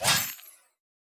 Special & Powerup (30).wav